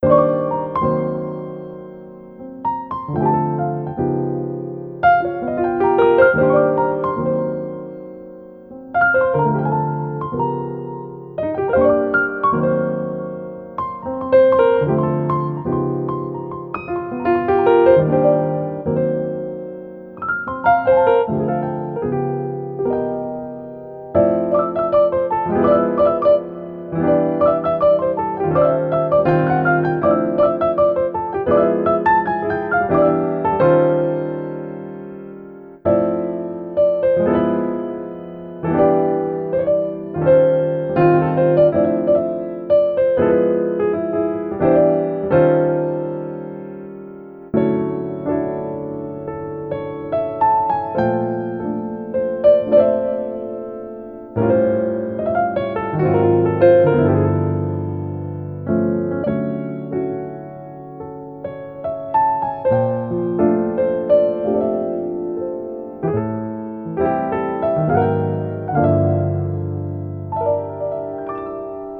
精选爵士钢琴和弦和乐句，非常适合R＆B、嘻哈和chill曲目。